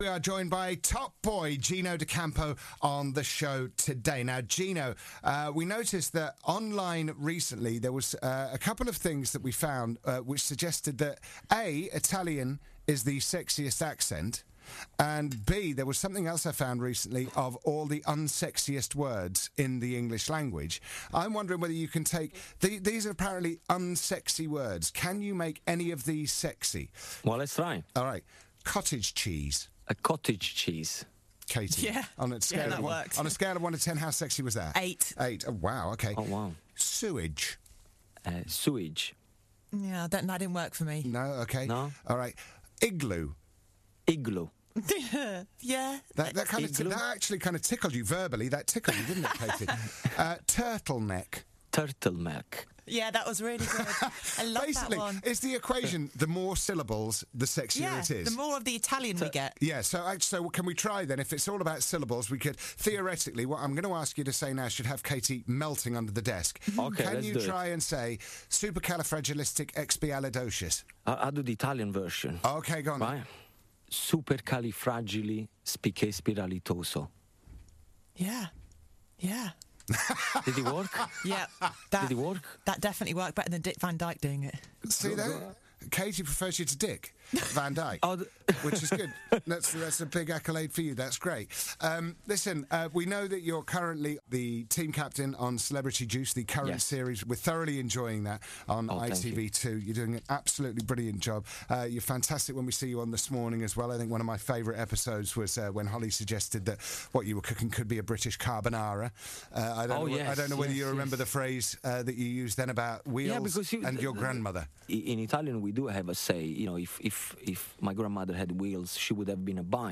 We find out how sexy the Italian accent really is!